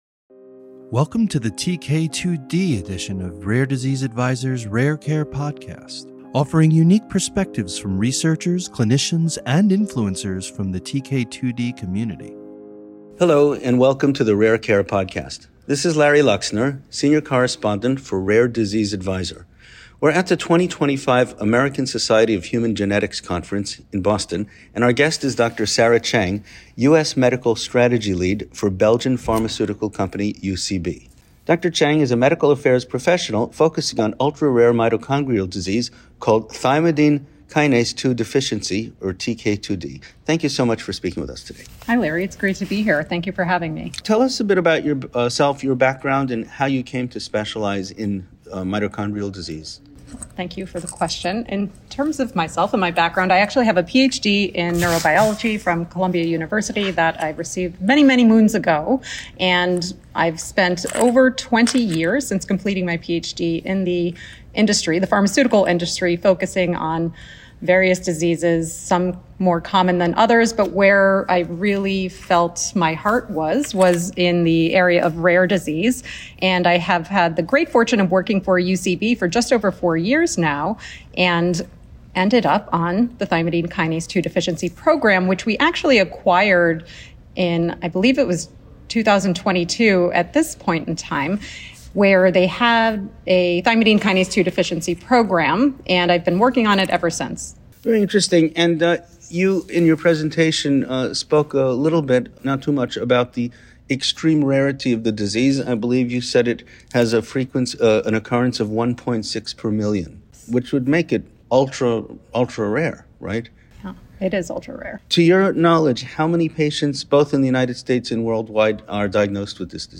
Rare Care Podcast / An Interview